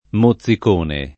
mozzicone [ mo ZZ ik 1 ne ]